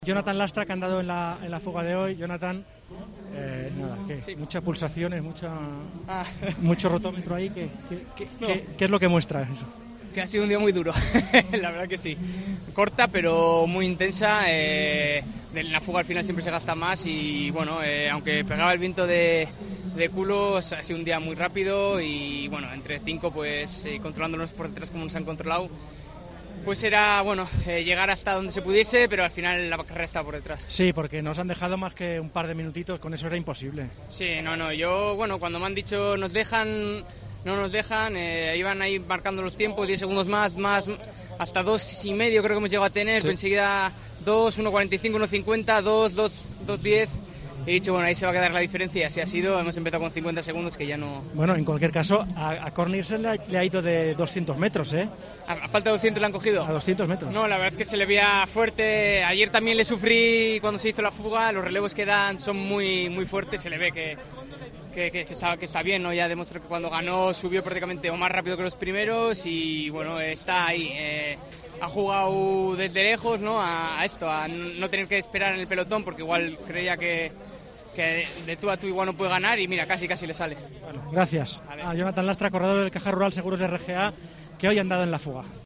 AUDIO: El ciclista ha comentado en COPE sus sensaciones en la fuga.